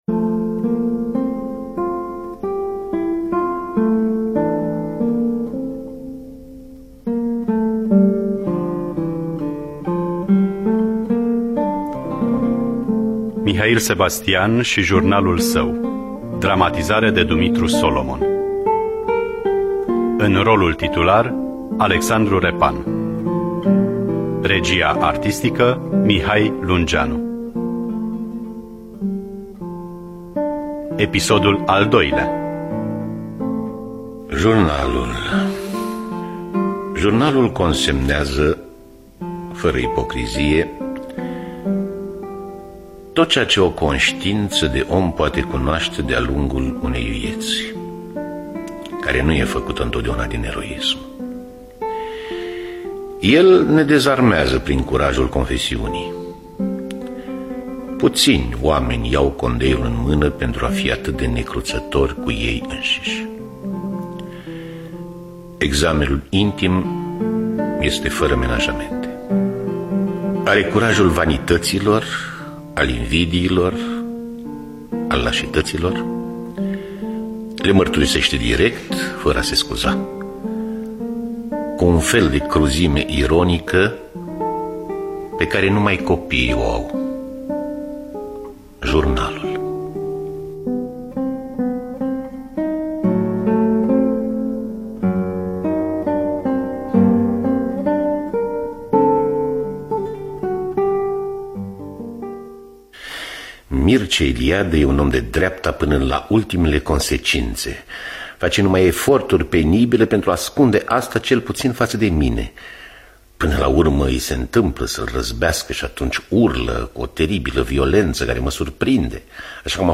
Partea a II-a. Scenariu radiofonic de Dumitru Solomon.